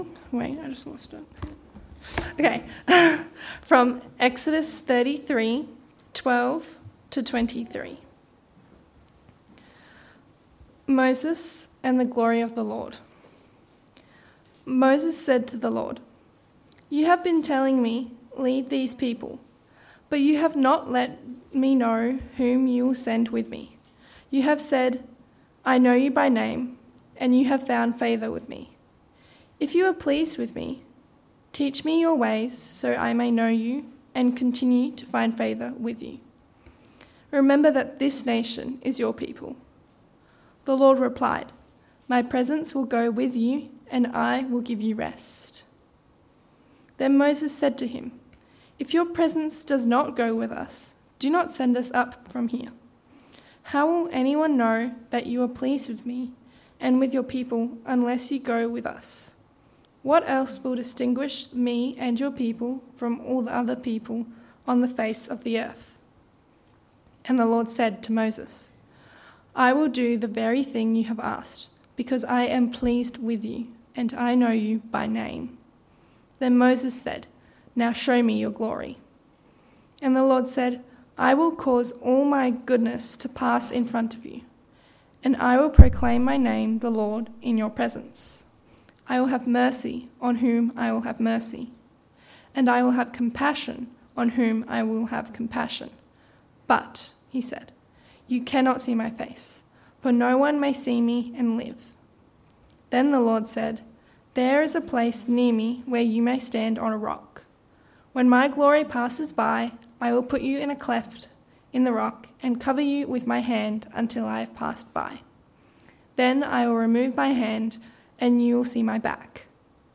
Text: Exodus 33: 12-23 Sermon